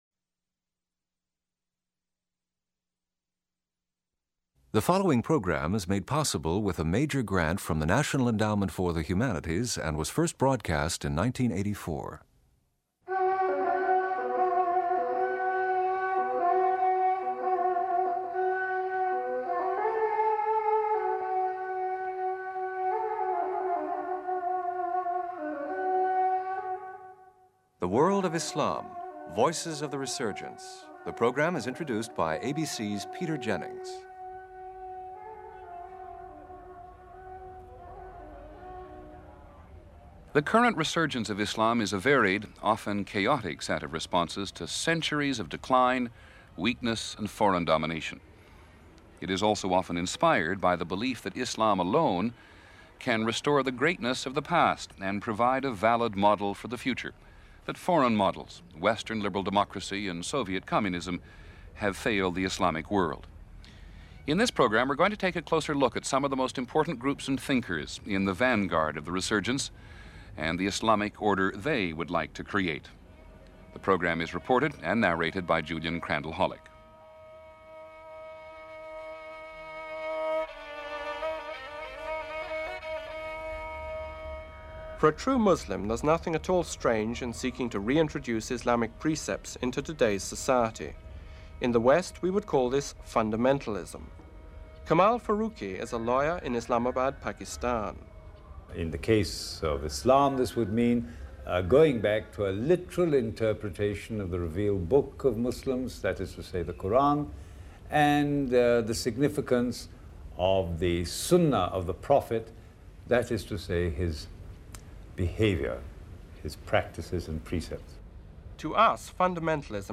Meet and listen to some of the most important groups and thinkers in the vanguard of the current resurgence, and at the Islamic order they would like to create.